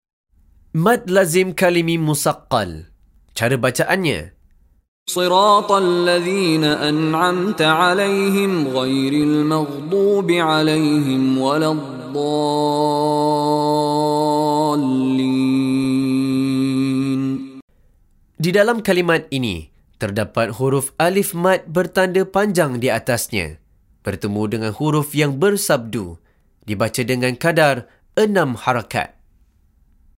Penerangan Hukum + Contoh Bacaan dari Sheikh Mishary Rashid Al-Afasy
Dipanjangkan sebutan huruf Mad dengan 6 Harakat sahaja.